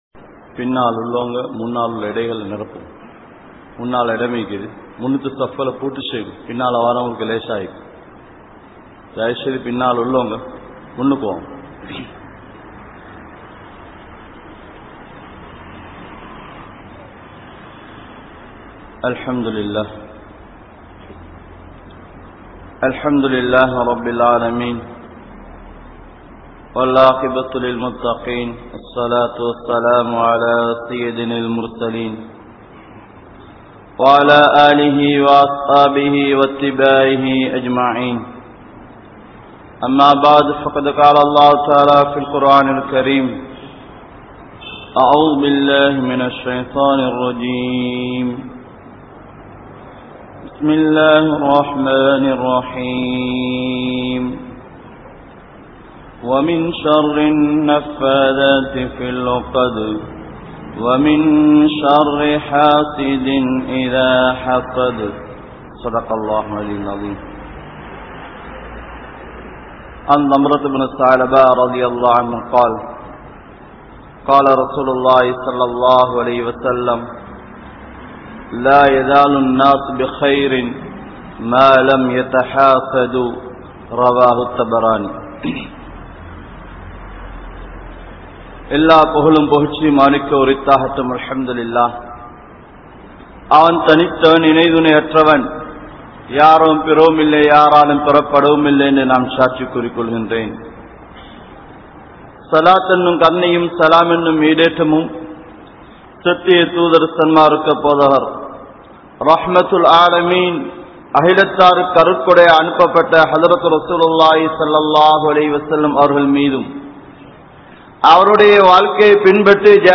Suththam(Cleaning) | Audio Bayans | All Ceylon Muslim Youth Community | Addalaichenai